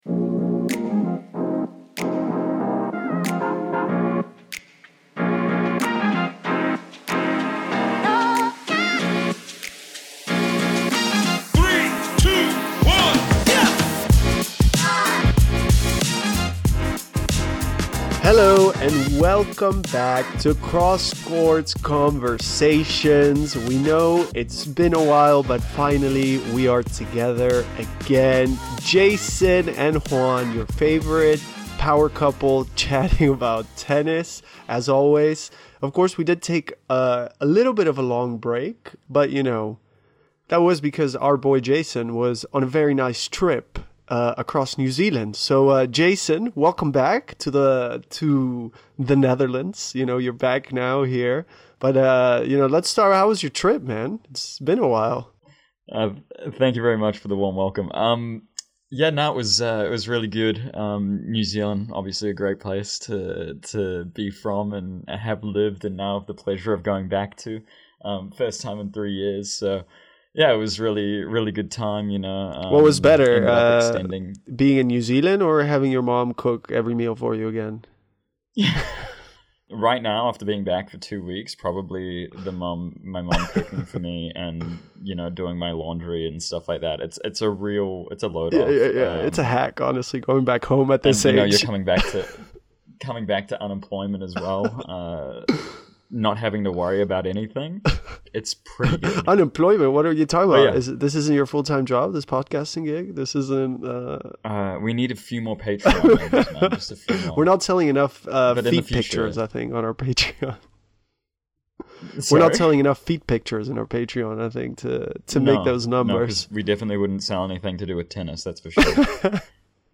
With a fresh perspective that adds a playful twist to the typical tennis talk, they blend match analysis, behind-the-scenes stories, and plenty of personal banter.